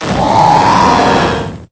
Cri de Dratatin dans Pokémon Épée et Bouclier.